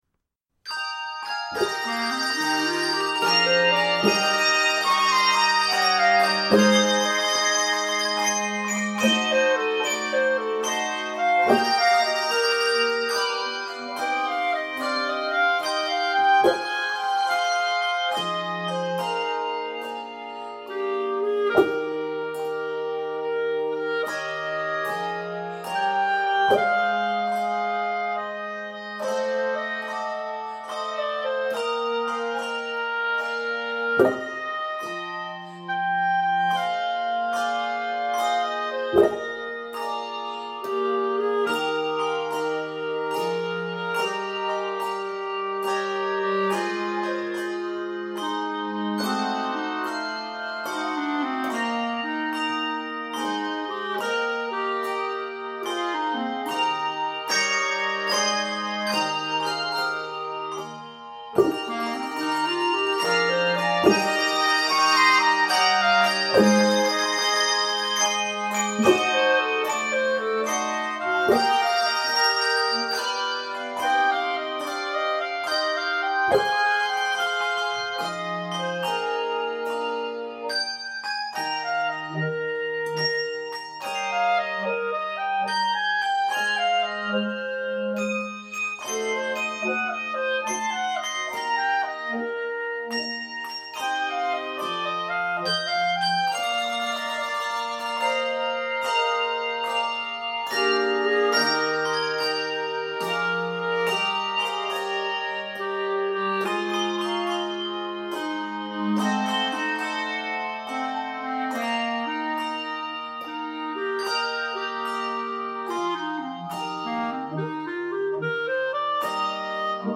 This piece is a swinging arrangement of the gospel standard